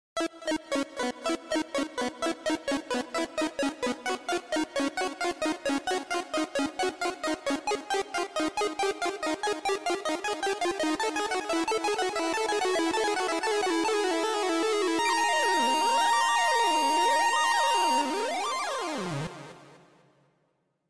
Копилка свинка с музыкой и звуком при опускании денег Money pig